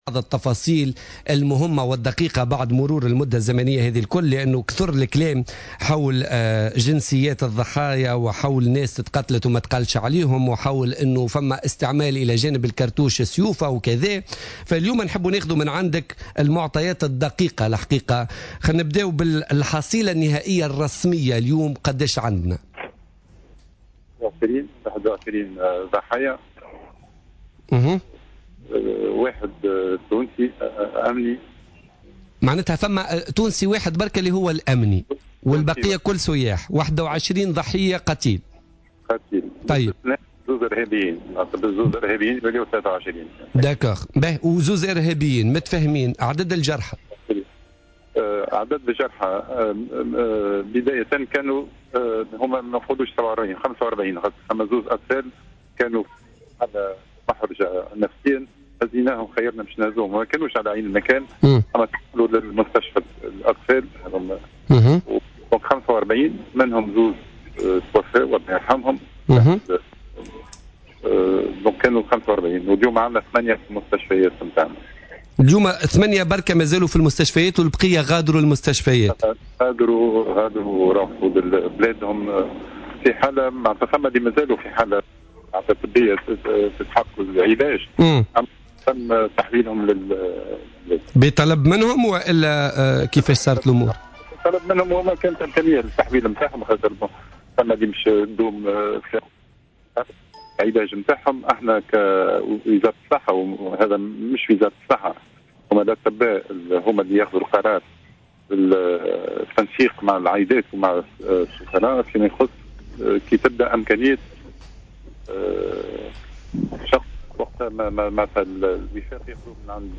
أكد وزير الصحة سعيد العايدي في مداخلة له في برنامج بوليتيكا اليوم الإثنين أن عدد...